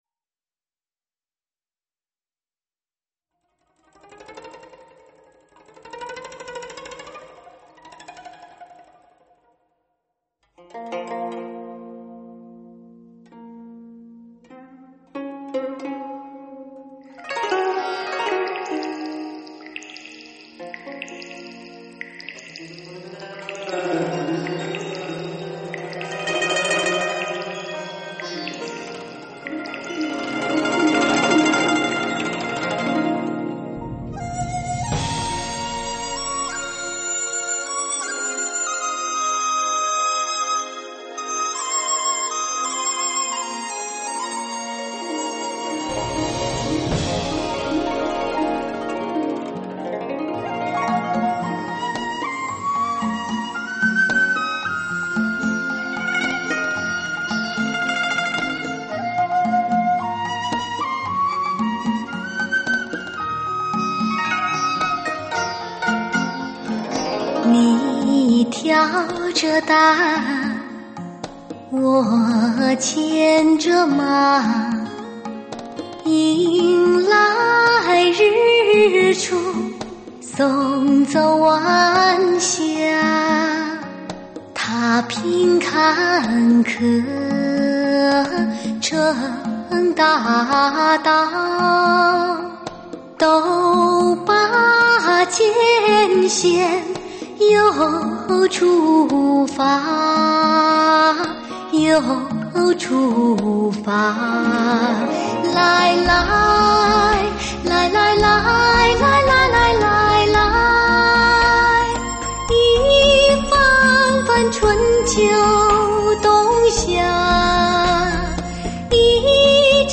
电声与民乐，古典与现代的完美结合，给发烧友的听觉带来强烈震撼和冲击力。